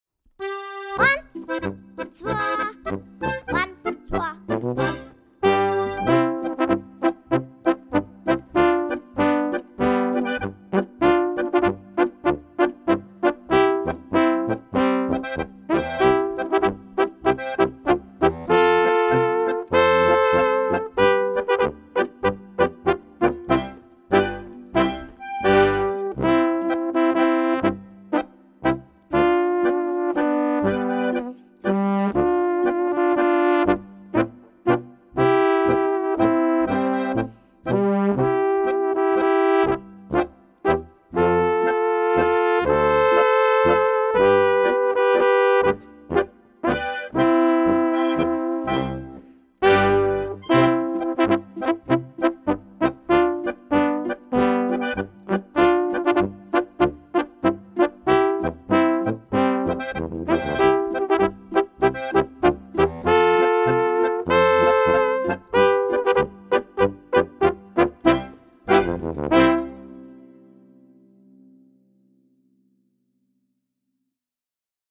Besetzung: Trompete